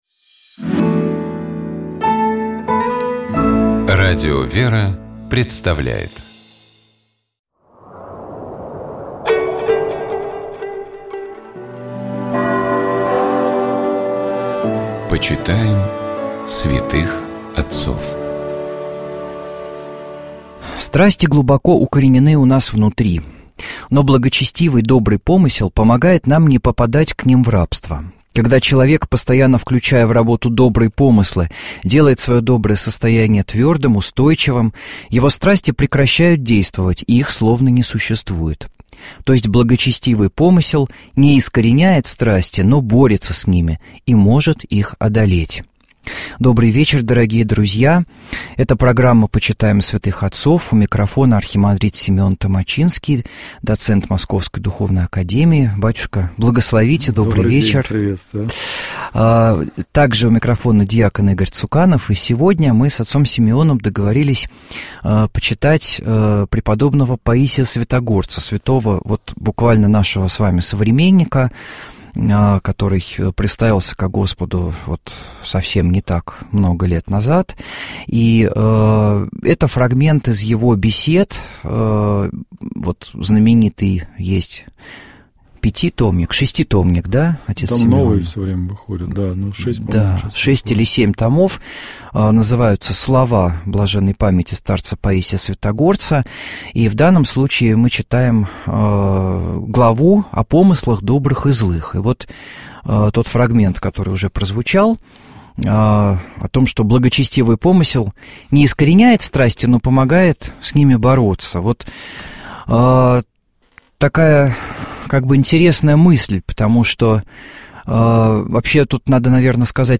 Ведущая программы: кандидат экономических наук